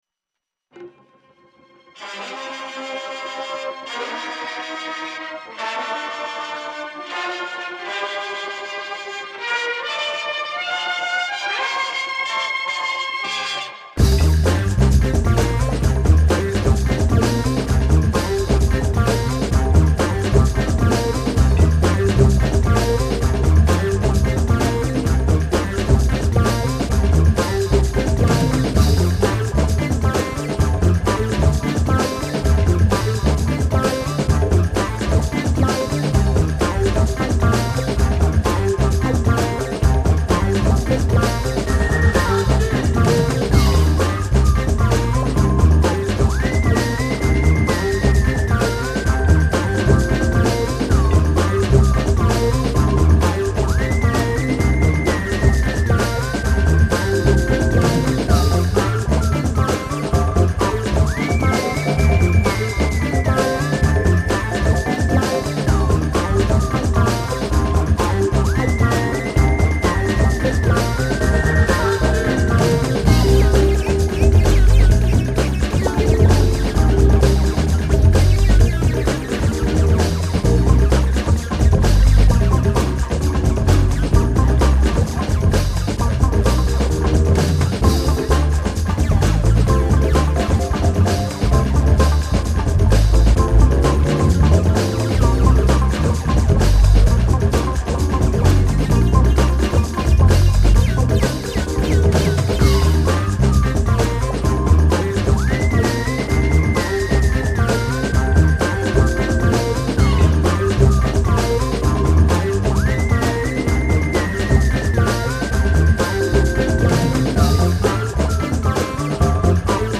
trochu breakbeatovy